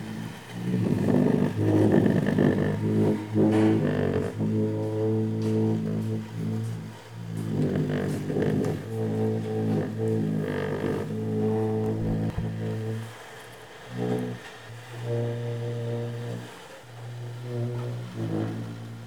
environnement_04.wav